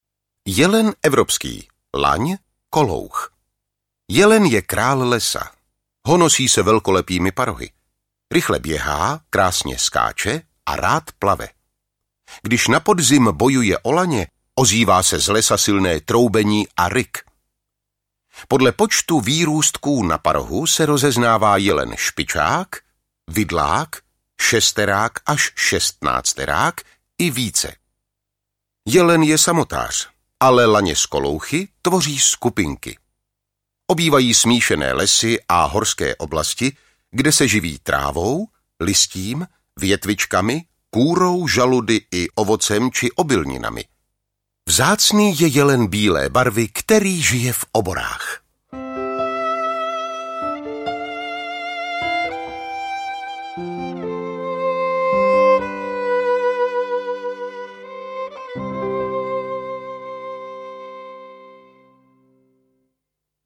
Za zvířátky do lesa audiokniha
Ukázka z knihy